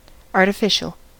artificial: Wikimedia Commons US English Pronunciations
En-us-artificial.WAV